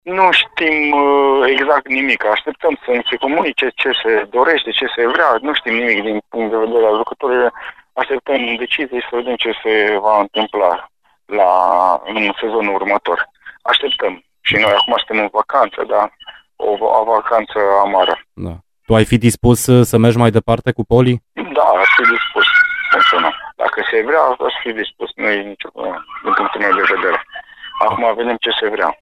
Invitat în weekend la Arena Radio